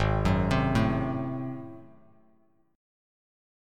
G#7b9 chord